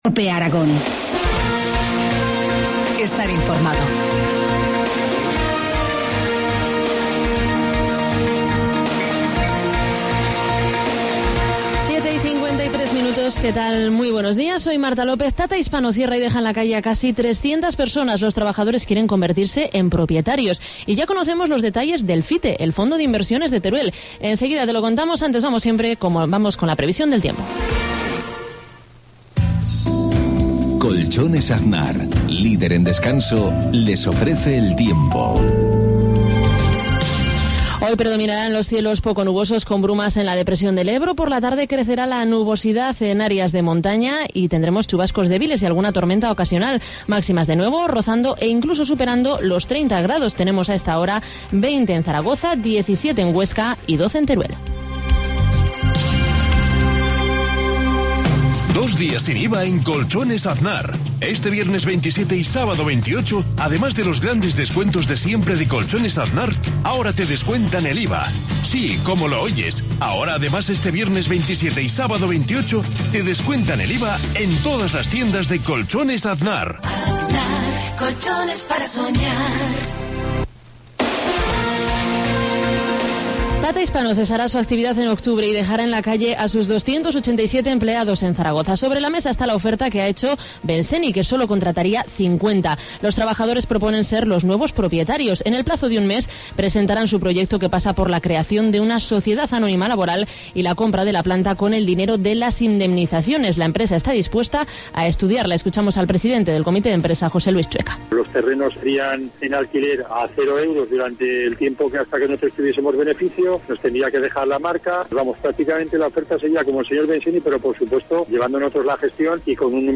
Informativo matinal, miercoles 25 septiembre, 2013, 7,53 horas